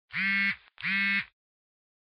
Звуки вибрации телефона, виброзвонка
Звук смс в беззвучном режиме